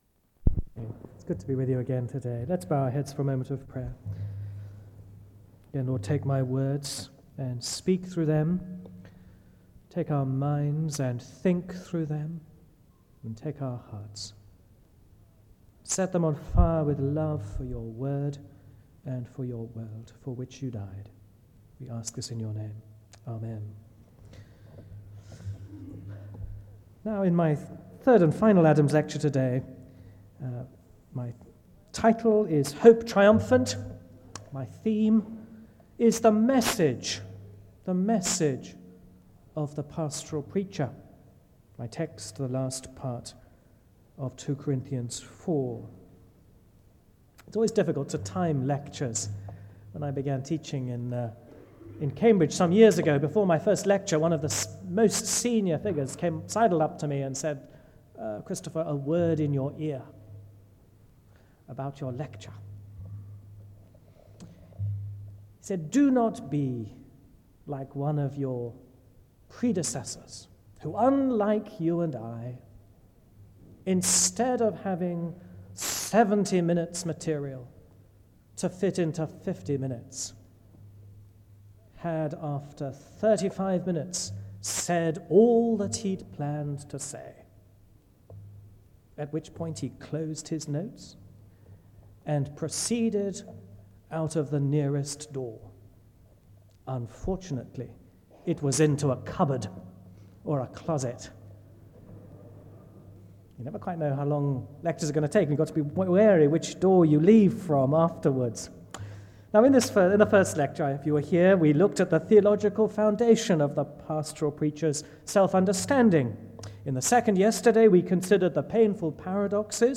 SEBTS Adams Lecture
SEBTS Chapel and Special Event Recordings